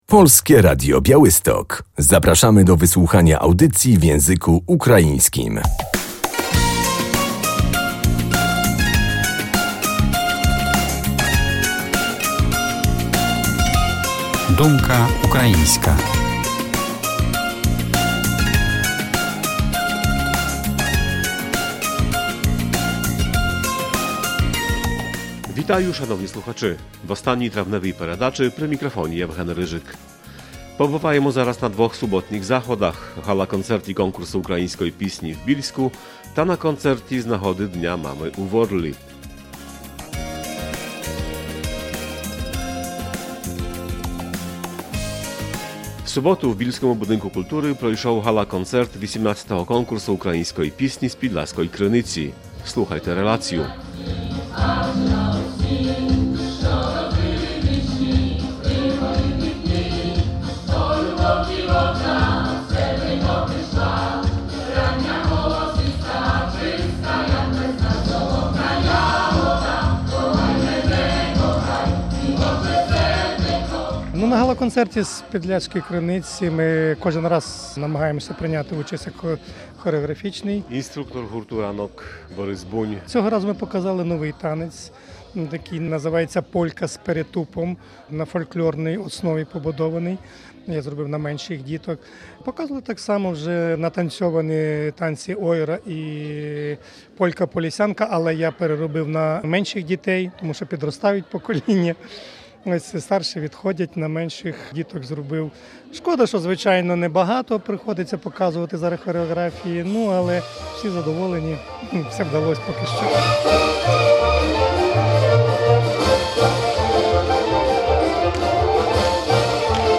Ponad 20 wykonawców - zespoły, soliści, tria, kwartety - zaprezentowało się w Bielskim Domu Kultury na koncercie galowym 18 konkursu piosenki ukraińskiej "Z podlaskiej krynicy".
Koncert galowy konkursu piosenki ukraińskiej na Podlasiu 30.05.2022